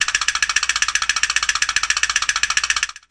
Звуки дрожания
Звук комичной дрожи от испуга, стук зубов